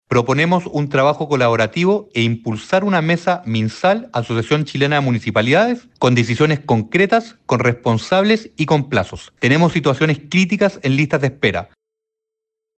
Por su parte, el alcalde de Huechuraba, Maximiliano Luksic, advirtió que el sistema sanitario enfrenta un momento crítico y que se requiere pasar de los diagnósticos a las decisiones. En esa línea, planteó la necesidad de crear una mesa técnica que permita abordar de manera concreta los problemas de la red, fijando plazos y responsabilidades claras.